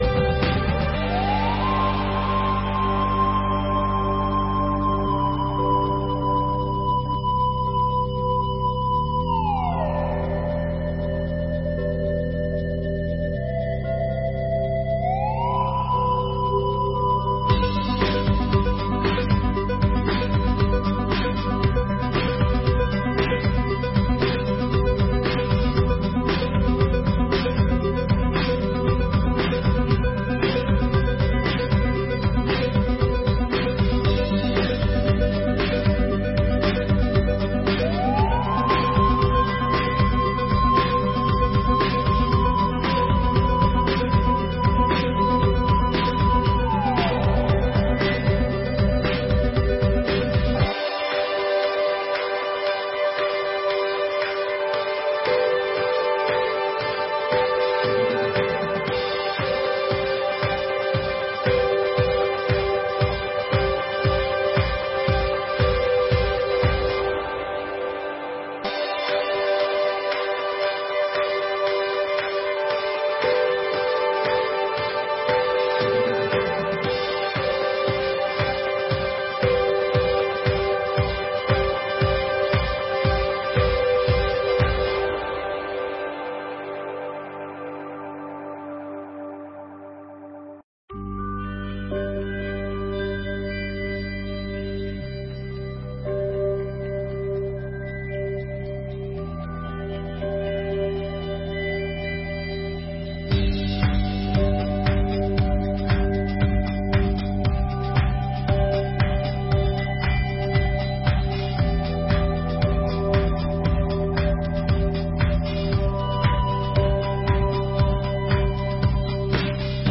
8ª Sessão Ordinária de 2024